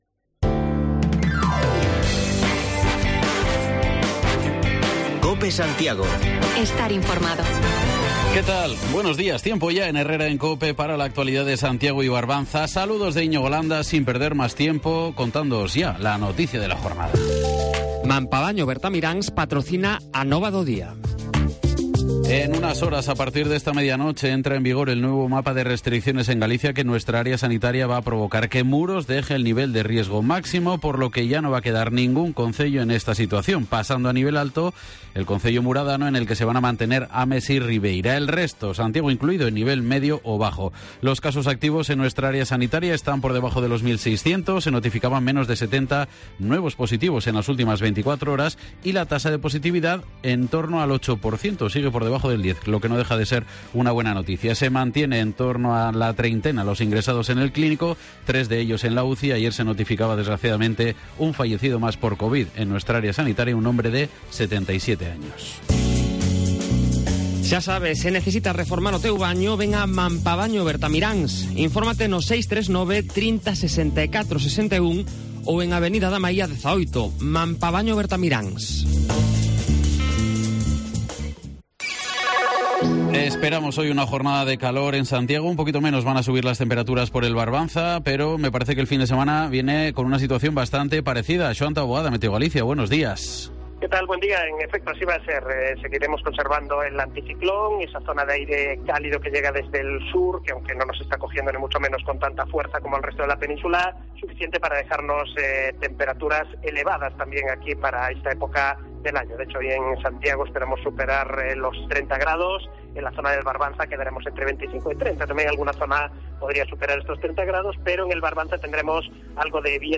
Repasamos la actualidad local de Santiago y Barbanza, con la previsión del tiempo para el fin de semana y charlamos con el alcalde de Ribeira, Manuel Ruis Rivas, sobre la agresión sufrida por agentes de la Policía Local, recibidos a pedradas cuando acudían a dispersar un botellón en Corrubedo